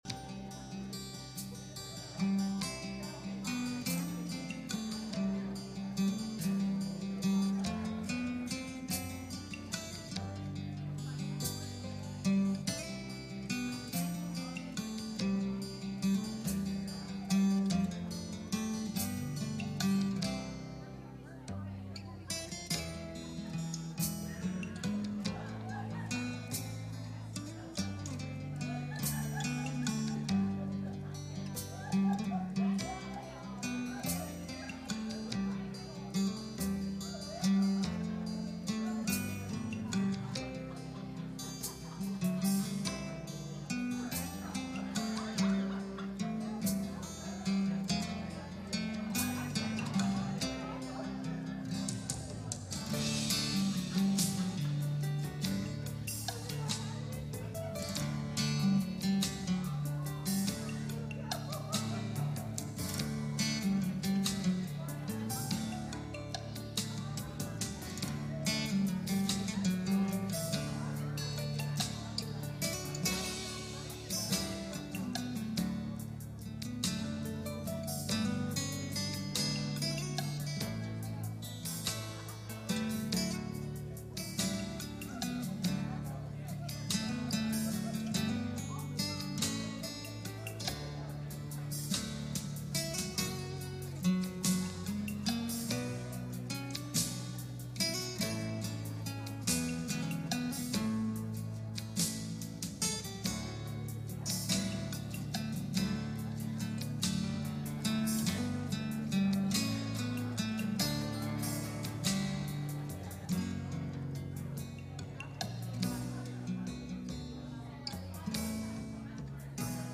Christmas Service